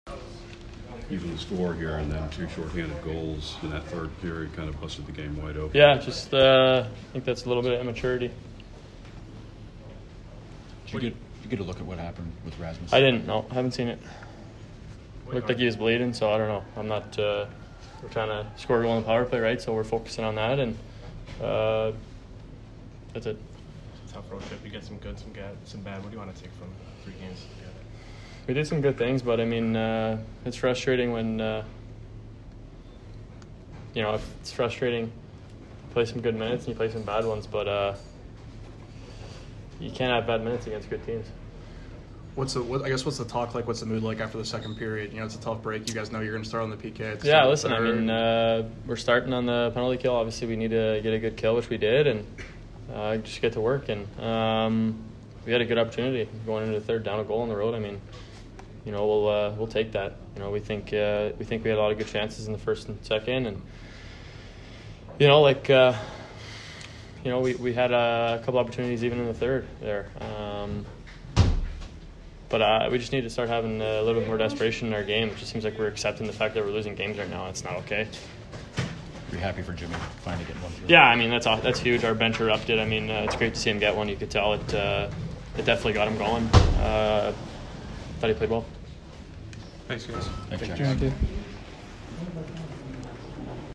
Eichel post-game 11/25